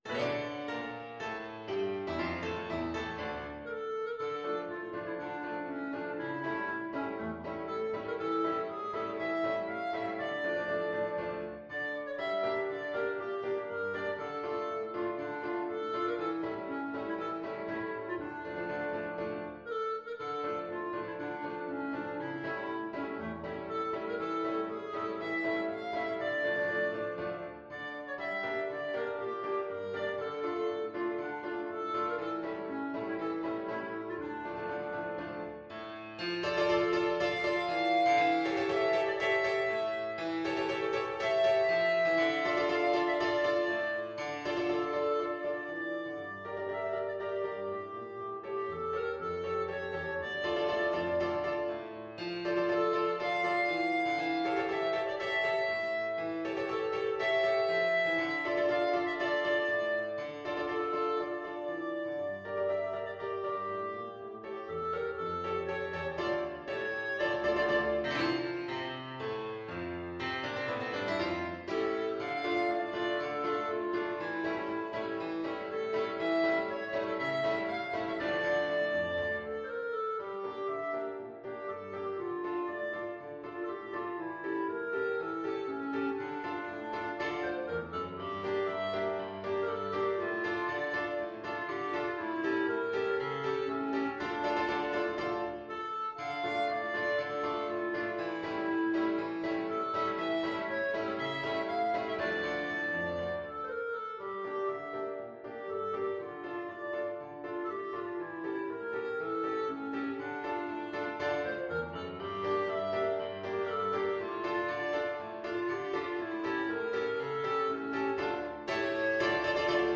Clarinet
2/2 (View more 2/2 Music)
D minor (Sounding Pitch) E minor (Clarinet in Bb) (View more D minor Music for Clarinet )
Allegro = c.120 (View more music marked Allegro)
Classical (View more Classical Clarinet Music)
farewell_of_slavianka_CL.mp3